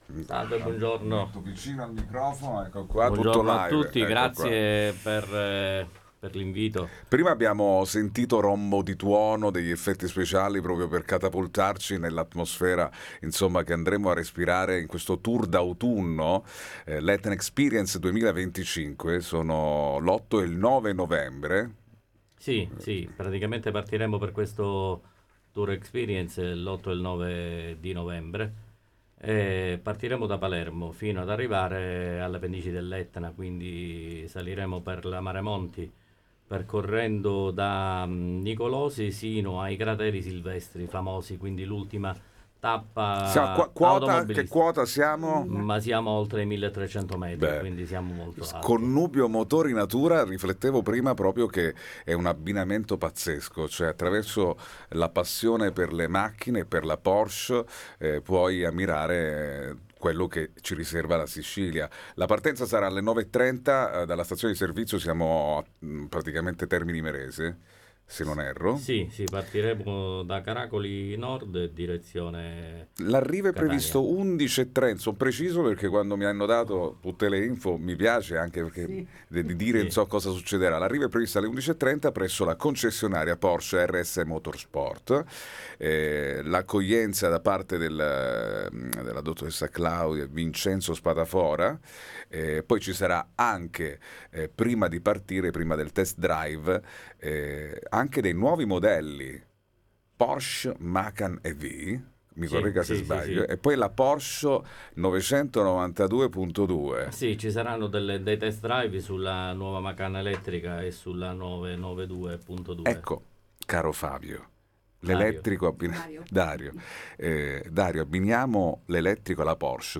Porsche Club Trinacria – Raid Etna All Inclusive Interviste 23/10/2025 12:00:00 AM / All Inclusive Condividi: Porsche Club Trinacria – Raid Etna, ne parliamo con alcuni dei partecipanti